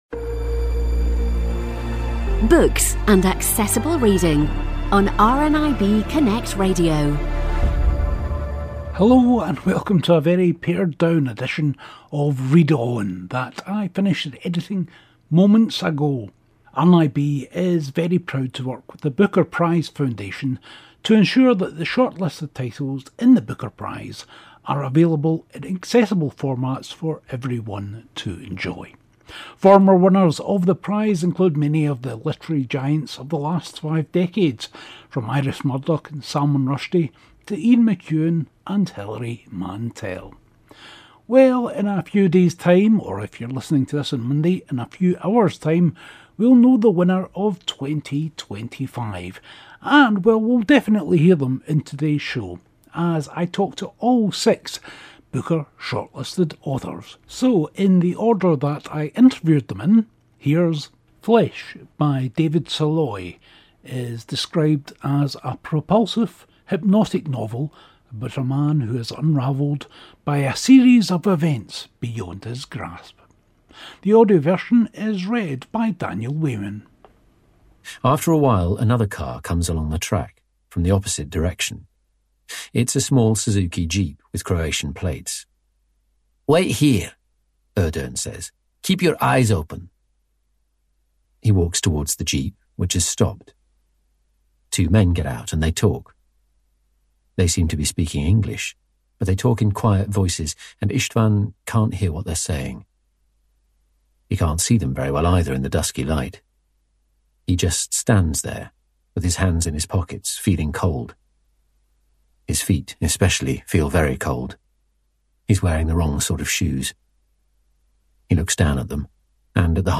In an episode recorded on location at the Wigtown Book Festival